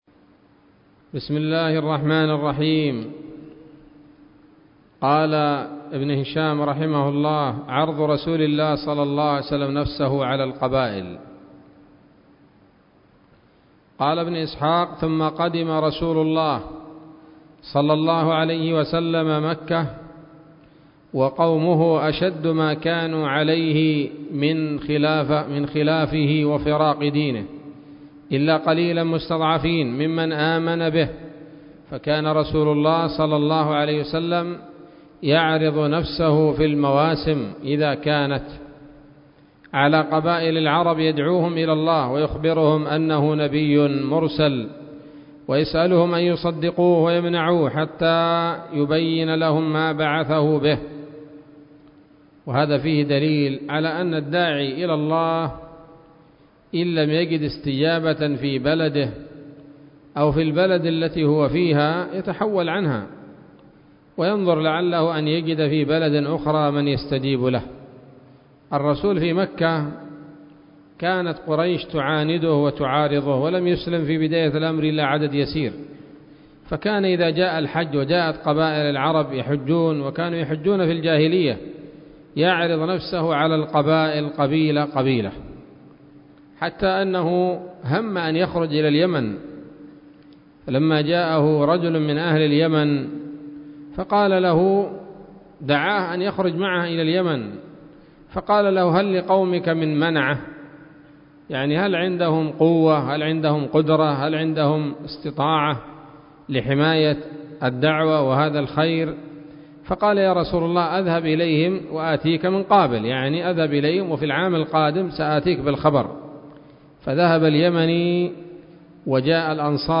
الدرس السابع والخمسون من التعليق على كتاب السيرة النبوية لابن هشام